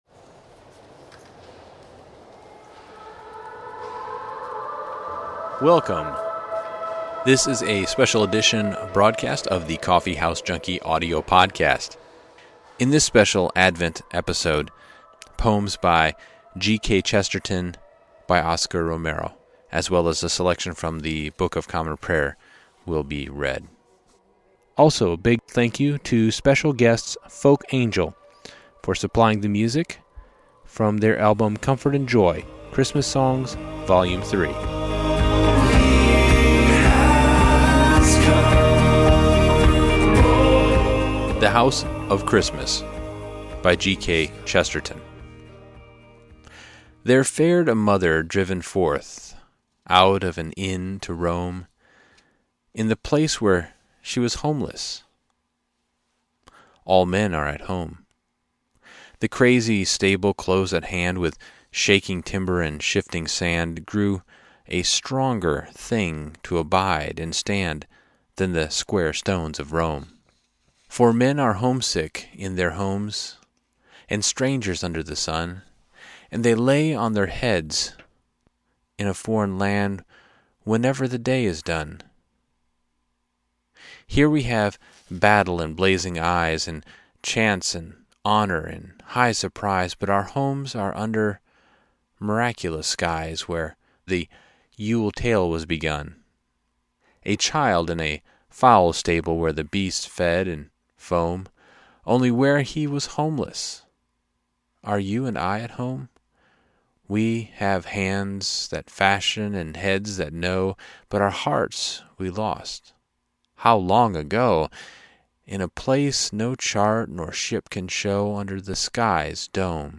FolkAngel_GladTidingsAlso, special thanks to Folk Angel for permission to use “O Little Town Of Bethlehem” from their album Glad Tidings – Christmas Songs, Vol. 4.